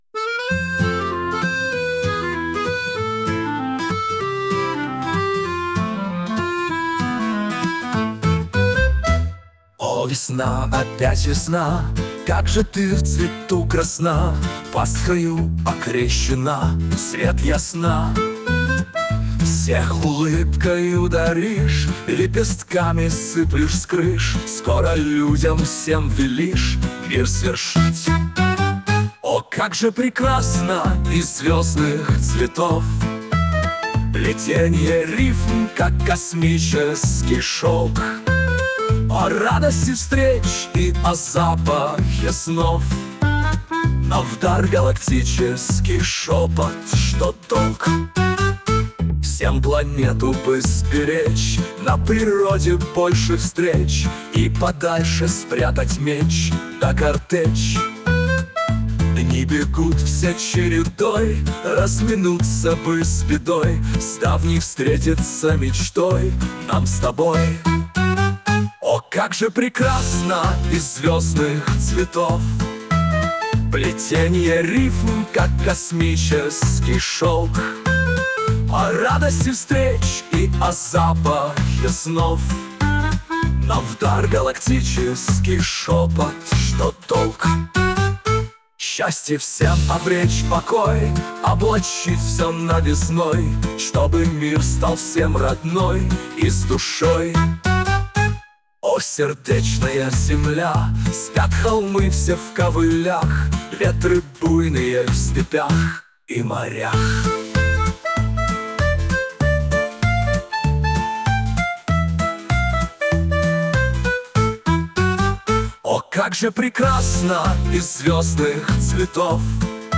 Мелодия на слова песни: